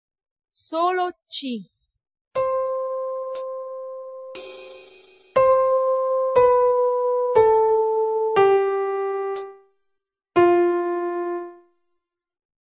Tutti i nostri dettati, fino alle modulazioni sono stati registrati sopra un ritmo di metronomo che pulsa nelle unità di tempo per le misure semplici e nelle suddivisioni per le misure composte.
c)", verrà fatta ascoltare come nota di riferimento,